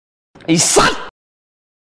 เสียงน้าค่อมด่า
คำอธิบาย: Download เสียงไอ้สัตว์ น้าค่อม ดาวน์โหลดเสียงเอฟเฟคน้าค่อมด่า mp3 เสียงน้าค่อมไอสัส mp3 4sh เสียงน้าค่อมสั้นๆ แก้ไขวิดีโอฟรี